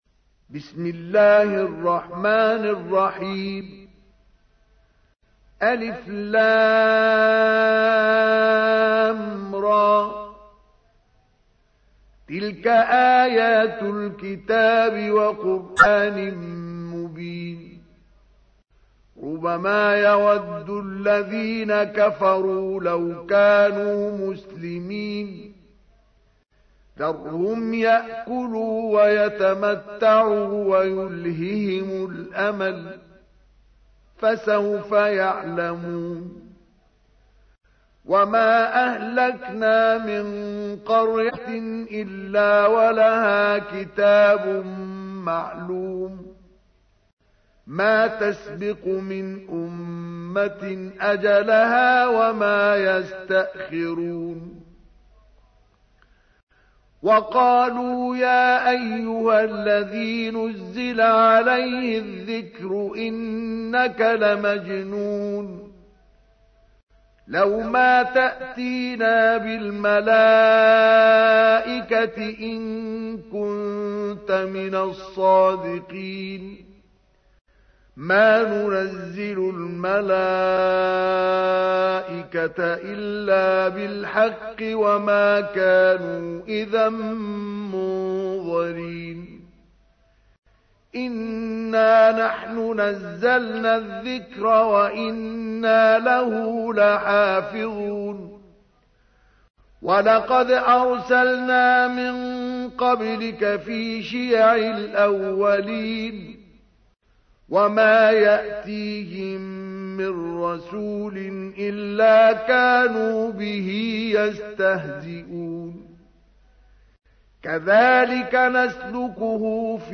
تحميل : 15. سورة الحجر / القارئ مصطفى اسماعيل / القرآن الكريم / موقع يا حسين